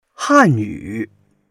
han4yu3.mp3